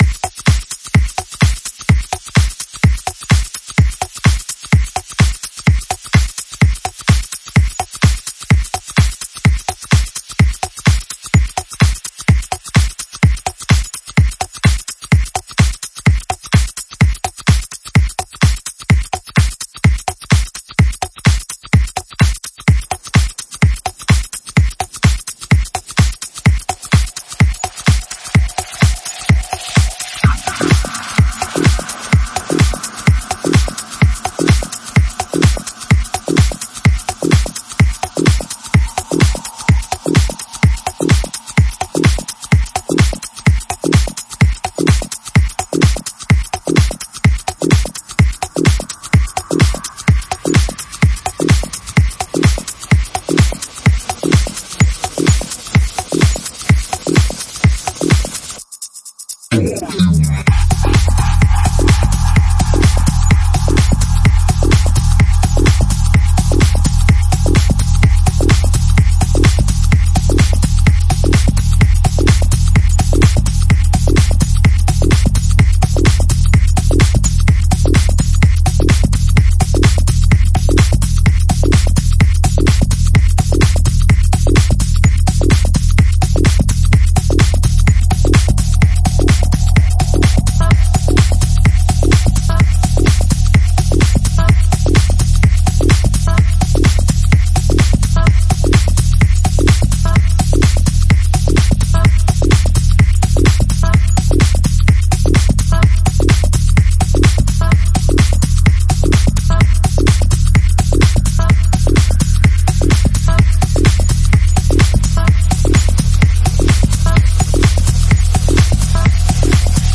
• A Techno Selection with Great artists!